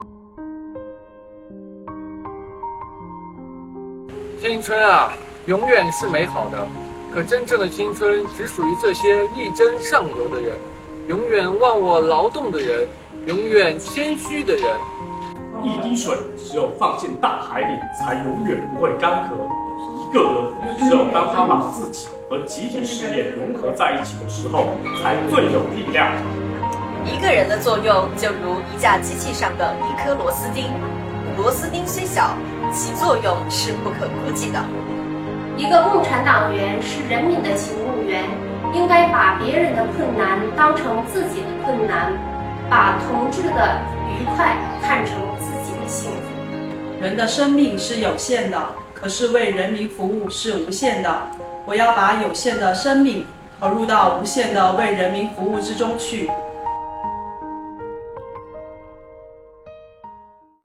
今年是第60个“学雷锋纪念日”和第3个“广西志愿者日”，为深入学习贯彻党的二十大精神和习近平总书记对深入开展学雷锋活动的重要指示精神，进一步培育和践行社会主义核心价值观，大力弘扬雷锋精神，在全市税务系统形成学习雷锋精神的良好风尚，防城港市税务局系统团委组织开展“读雷锋语录 学雷锋精神”活动，下面由防城港市港口区税务局的青年们带我们一起重温雷锋经典语录~